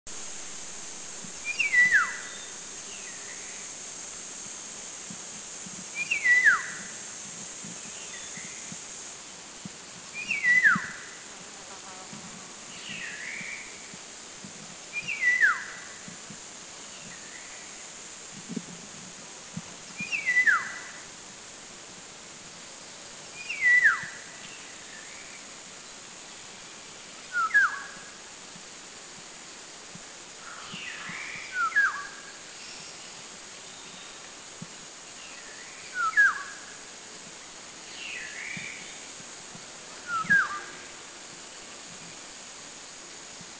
Black-naped Oriole   Oriolus chinensis
B2A_Black-napedOrioleSubic210_SDW.mp3